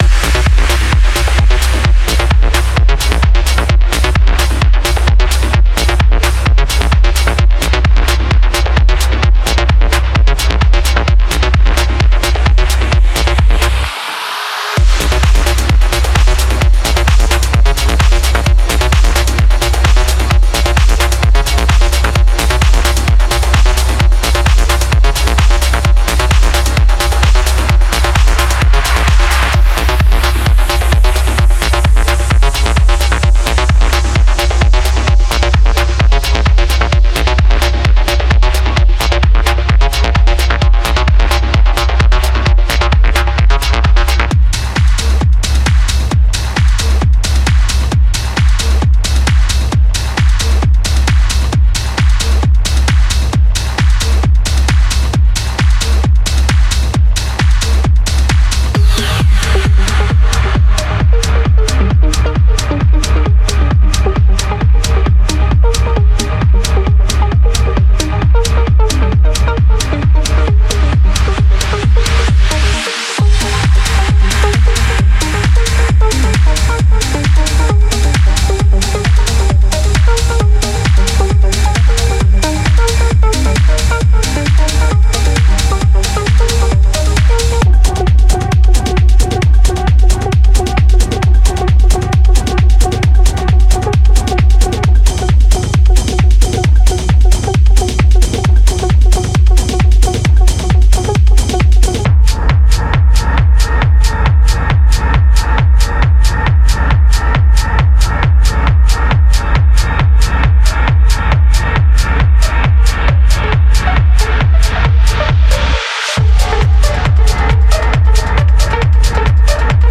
デモサウンドはコチラ↓
Genre:Techno
89 Drum Part Loops
30 Sound Effects
55 Melody Loops